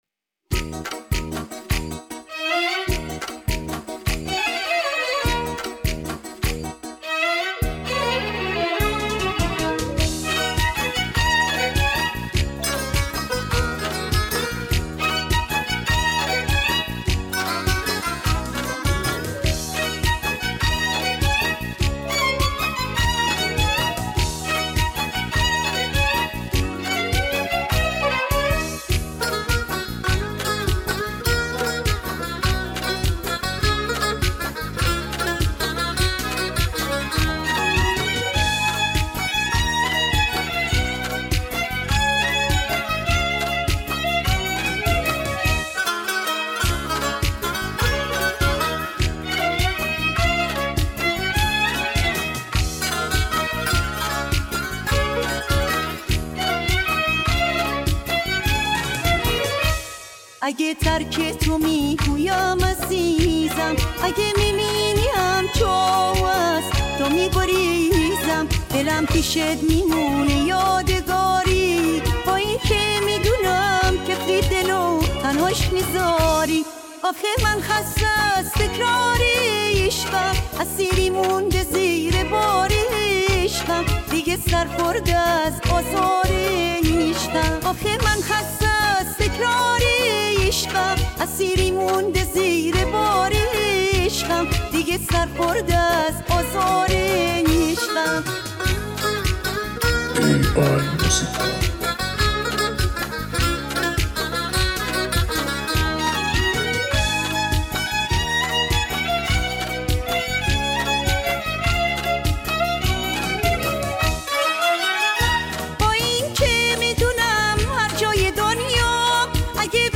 اهنگ شاد ایرانی
اهنگ غمگین